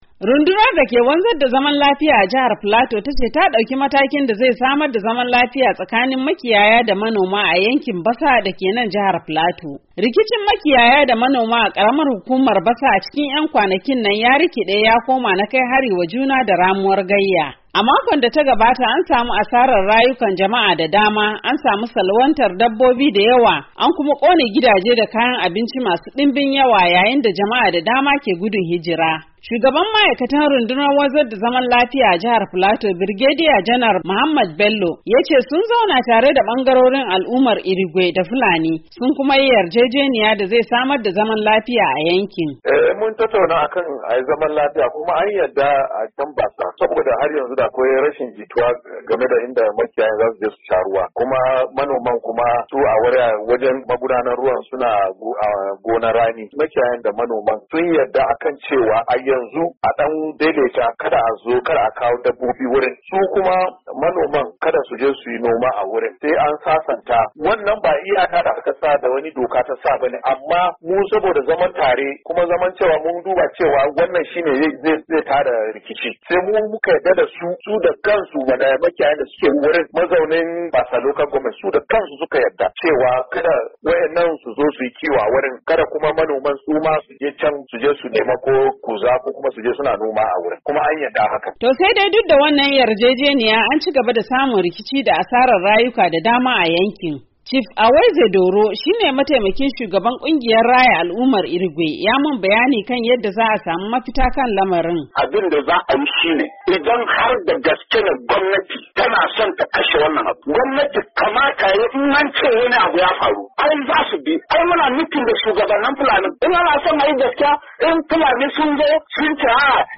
cikakken rahotan